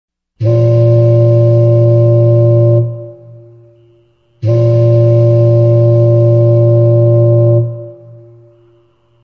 إستماع وتحميل مجموعة نغمات منوعة (mp3) رنات للجوال (mobile ringtone download) صوت الباخرة – نغمات للجوال the ship sound ringtones.
2 – صوت الباخرة ( Trumpet ship )